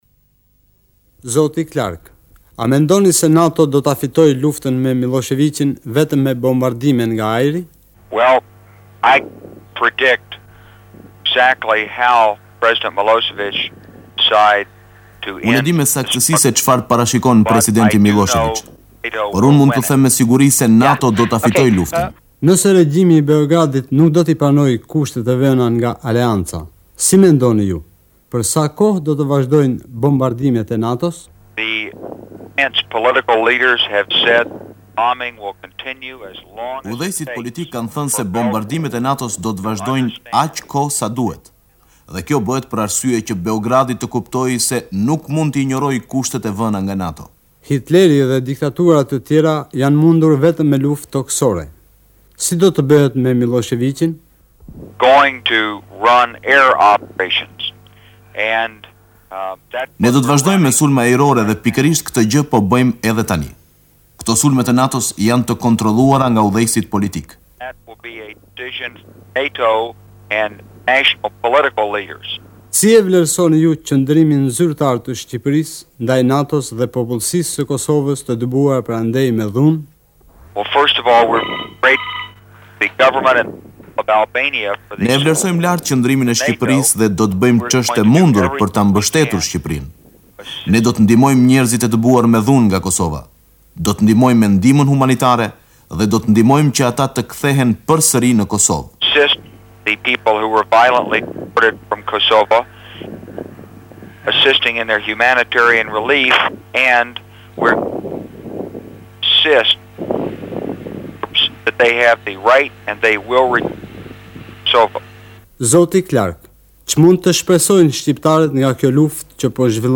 Radio-Tirana dëgjohej në Kosovë përmes transmetimit në AM, në valët 468 m me transmetues 50 KW; Radio-Kukësi gjithashtu në AM 303 m.